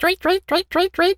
bird_tweety_tweet_03.wav